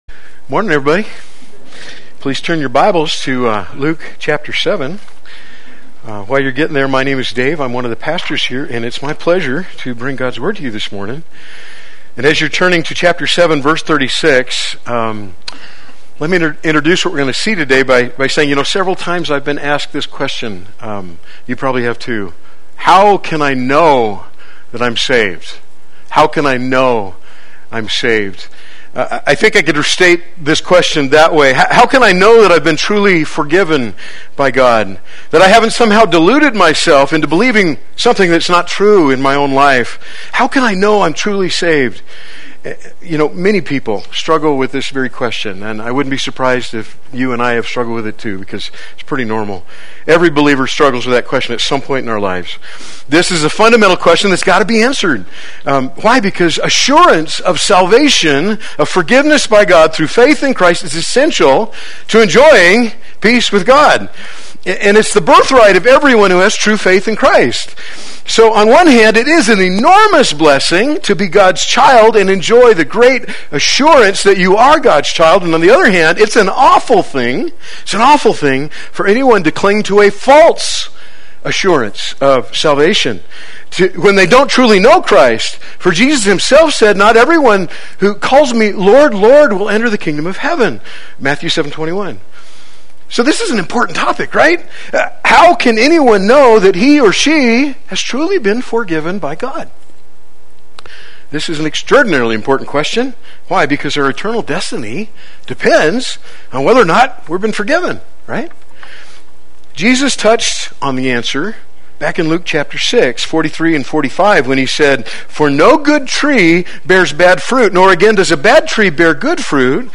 Play Sermon Get HCF Teaching Automatically.
The Fruit and Foundation of Forgiveness Sunday Worship